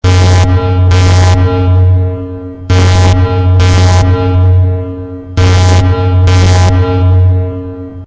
.核武警告.ogg